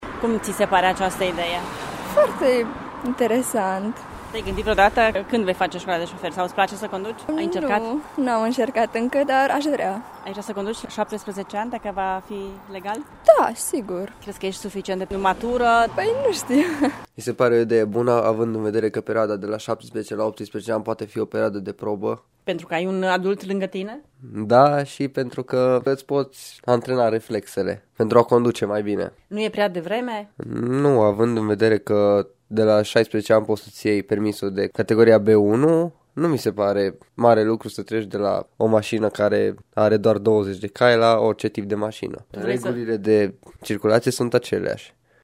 Inițiativa este pe placul adolescenților care spun că ar fi în avantajul lor: